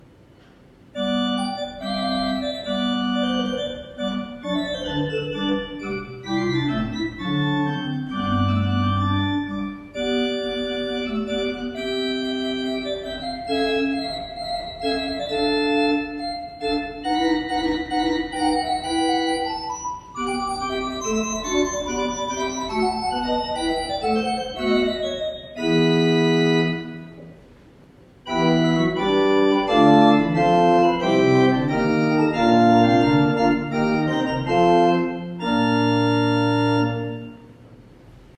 Aliquotmischungen
Das Instrument befindet sich in gutem Zustand, ist recht mild intoniert und daher für kleine Kirchenräume oder als Haus- und Übeorgel gut verwendbar.